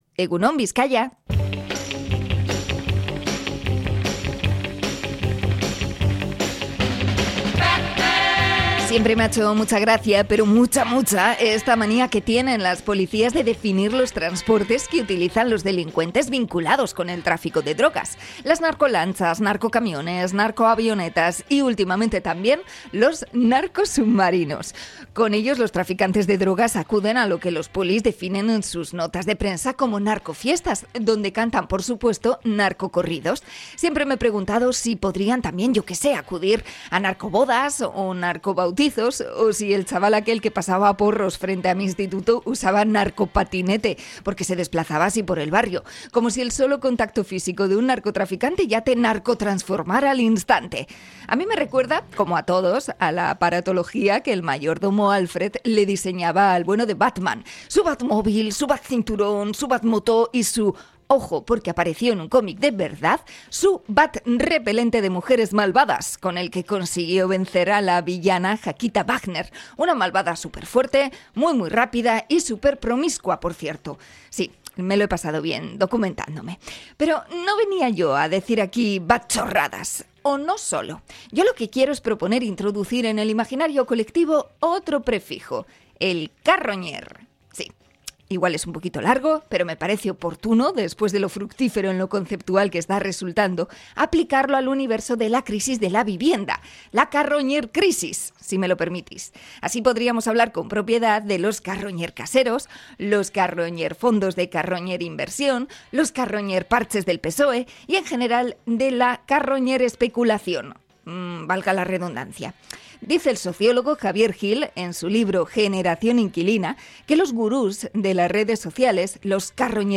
Comentario sobre los prefijos y la crisis de la vivienda